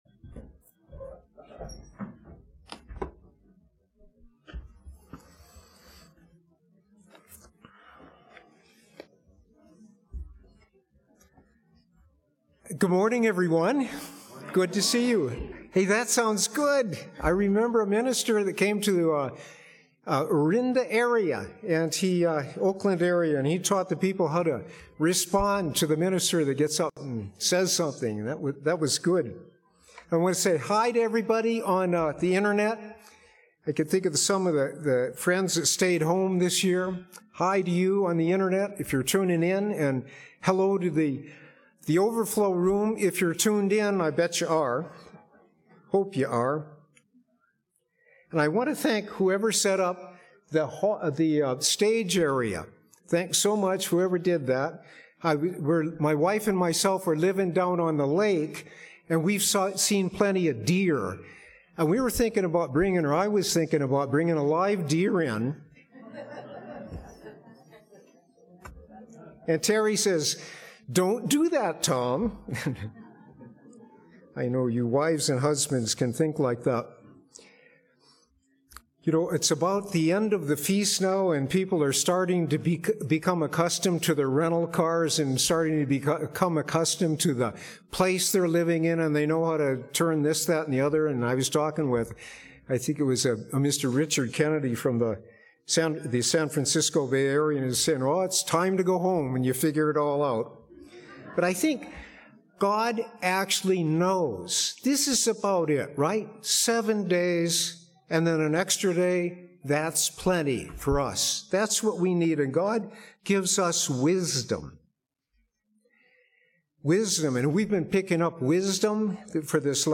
Split Sermon 1 - Day 7 - Feast of Tabernacles - Klamath Falls, Oregon
This sermon was given at the Klamath Falls, Oregon 2024 Feast site.